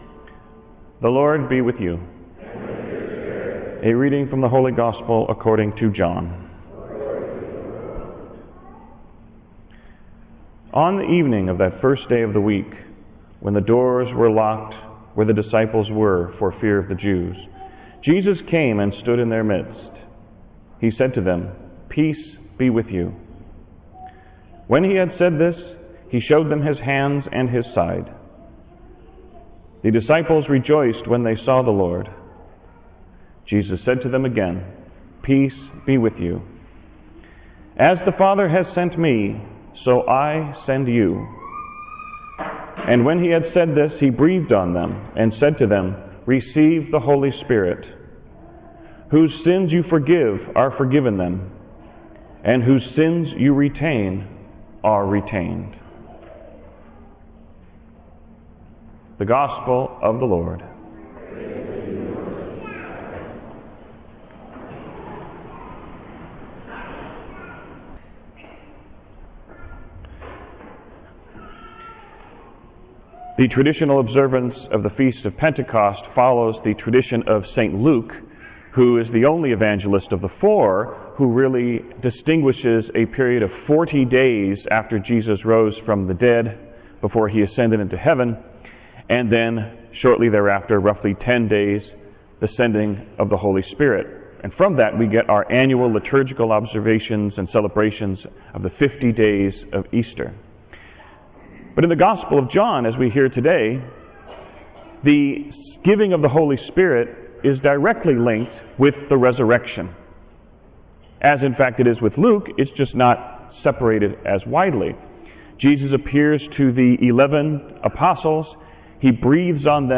Pentecost Sunday Homily – (audio) “The Wind”
Homily-PENTECOST2017wind2.wav